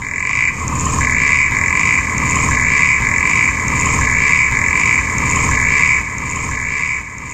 corroboreefrogv1.mp3